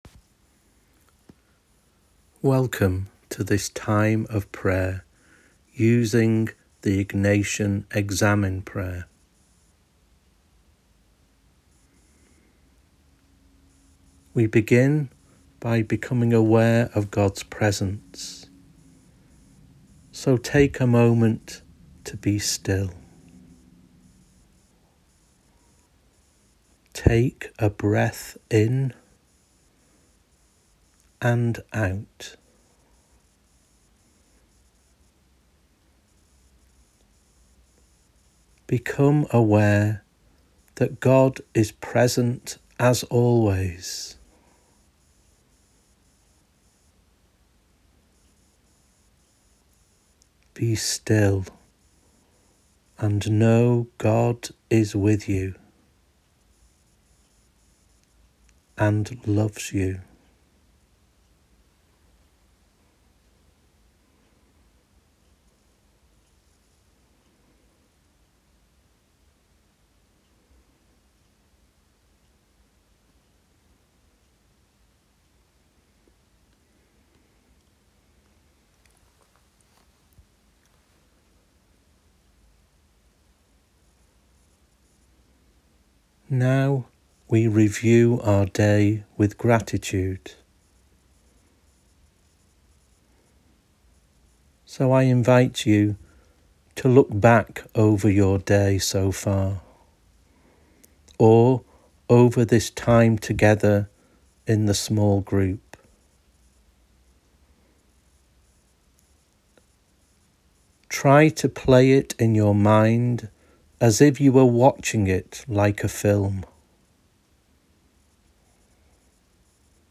Prayer Audio Recording: